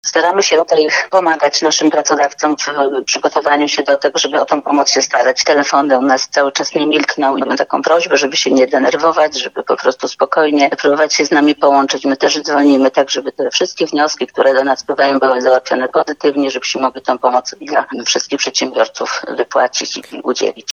w rozmowie na naszej antenie